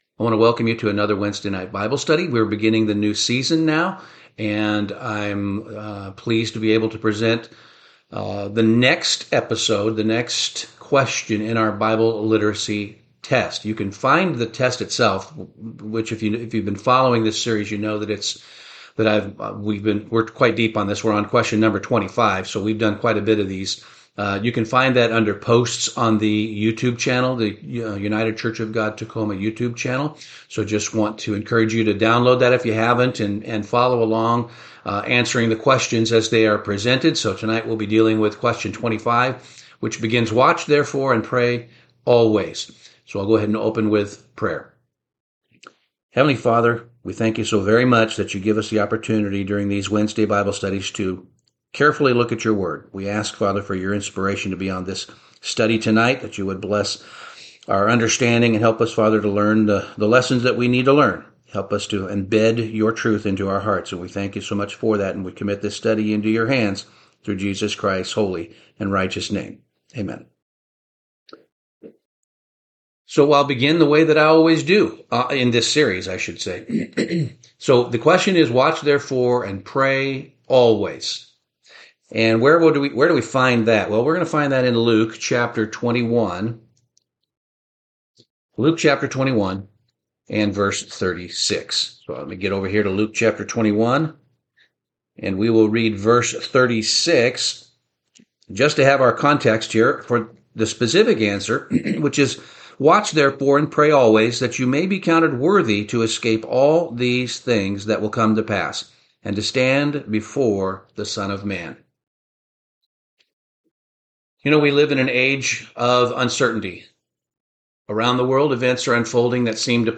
NW Bible Study - Bible Quiz #25, Watch and Pray